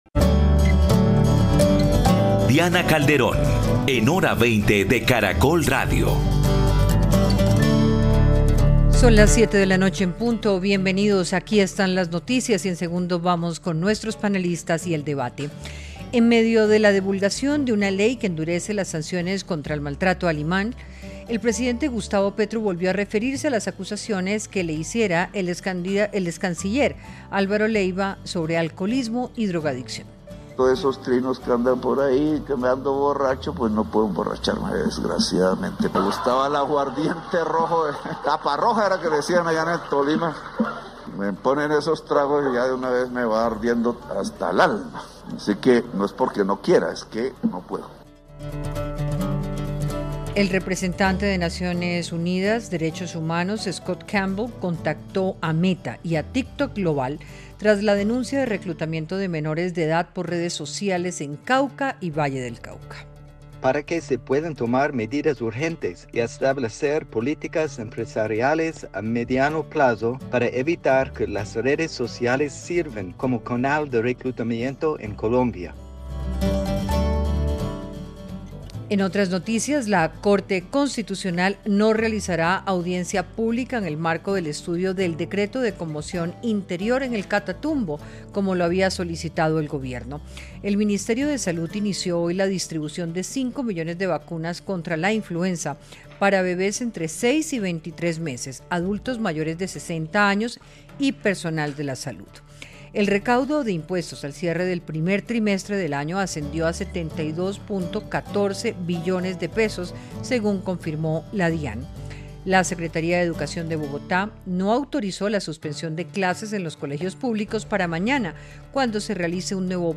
Panelistas analizaron el impacto político que ha tenido la carta que hizo pública el excanciller en la que acusa al presidente de consumir drogas, de no escuchar a los ministros y de desorden en el Gobierno.